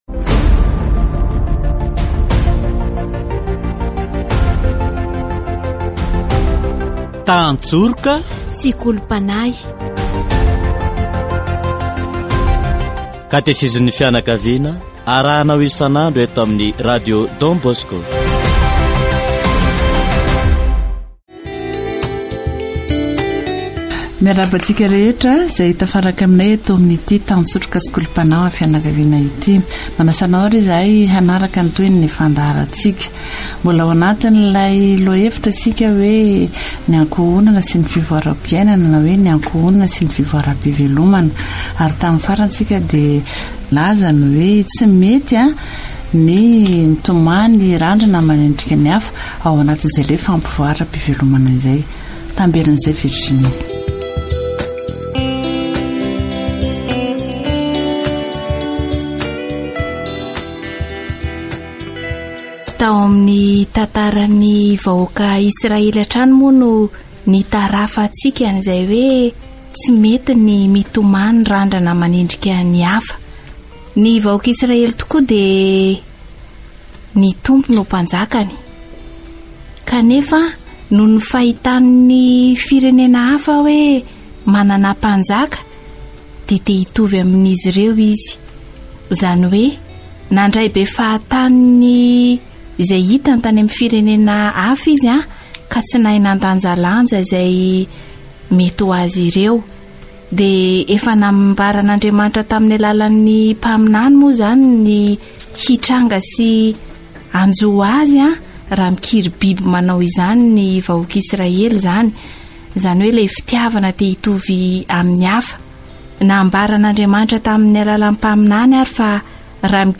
Catégorie : Approfondissement de la foi